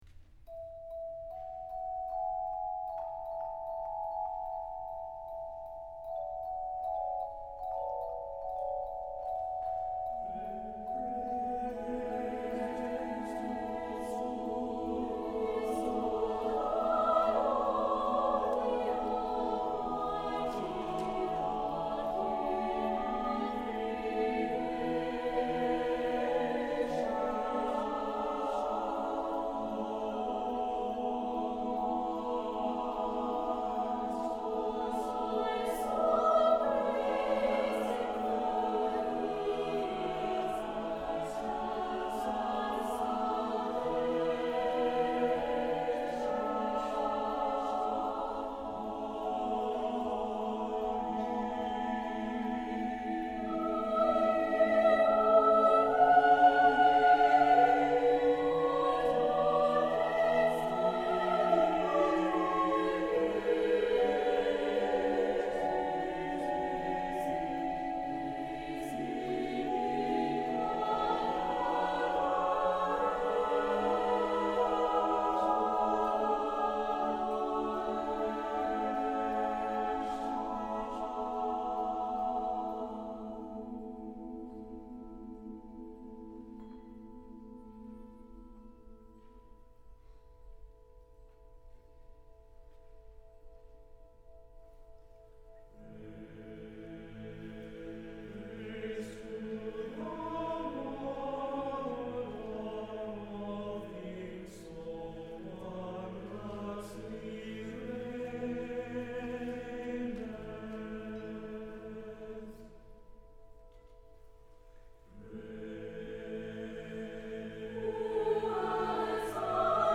Voicing: SATB and Handbells